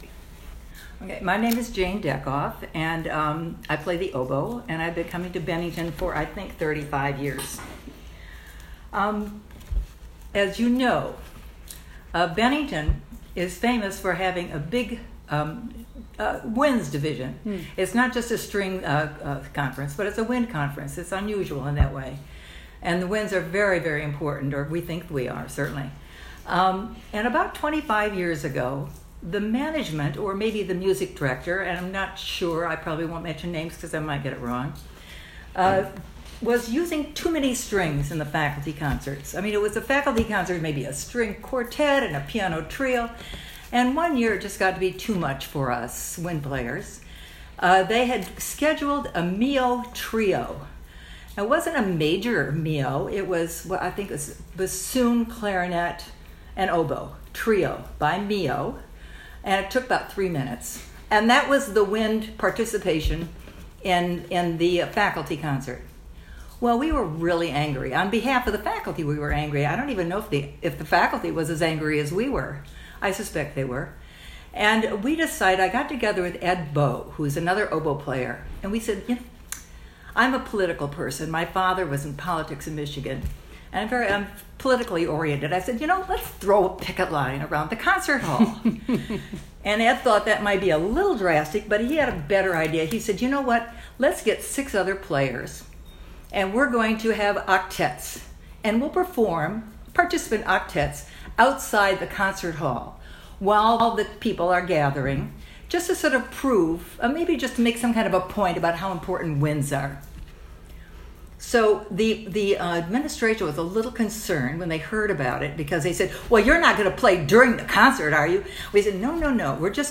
CMC Stories was initiative to collect oral histories from the CMC community.